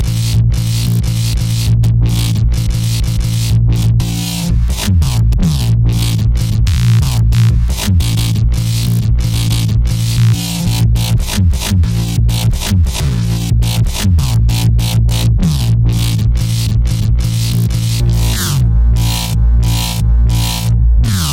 dubstep噪音，hip hop节奏。
Tag: 90 bpm Dubstep Loops Bass Wobble Loops 3.59 MB wav Key : Unknown